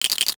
NOTIFICATION_Rattle_02_mono.wav